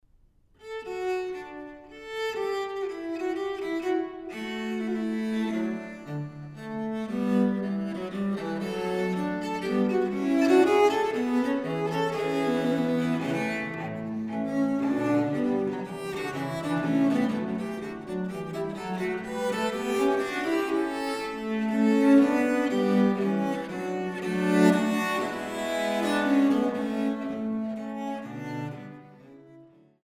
Suite in D für 2 VdG (Durham MS. D.10): Courante (XX)